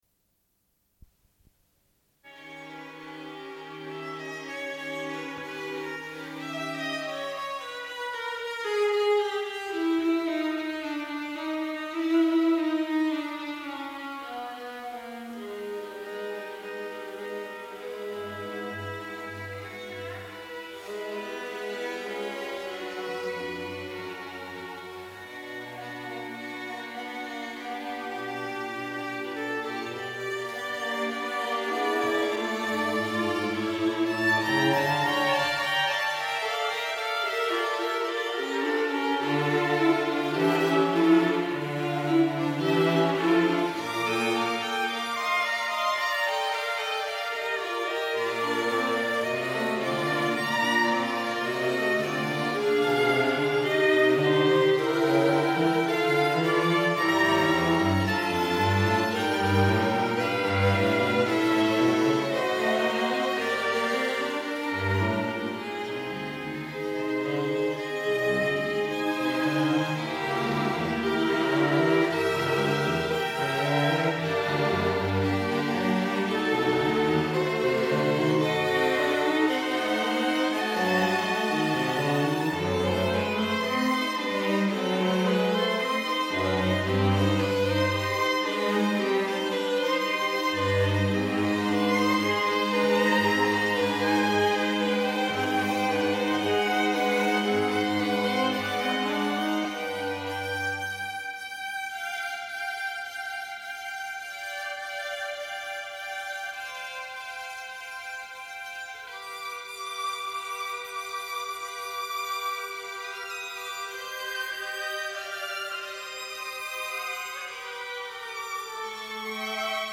Une cassette audio, face A31:15